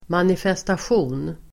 Uttal: [manifestasj'o:n]